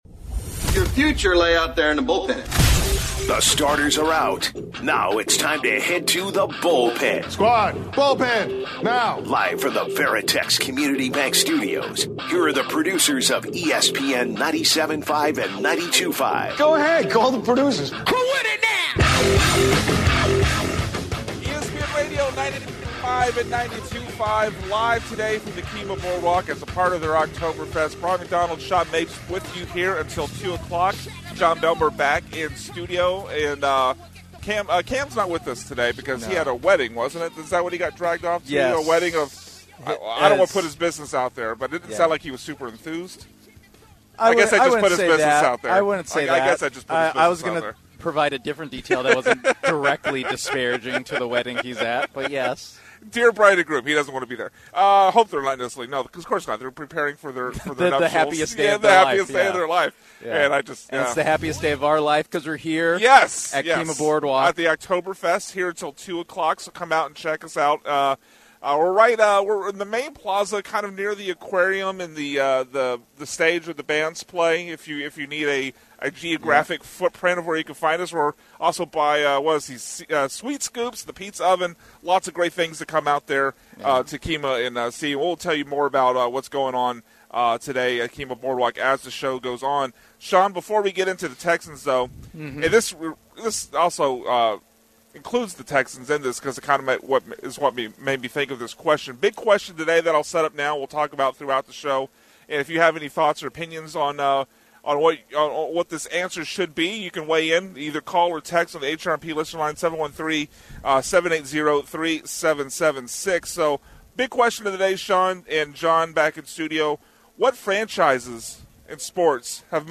10/04/25 Hour 1(Live from Kemah Boardwalk)- Texans favored against Baltimore, but is it too good to be true?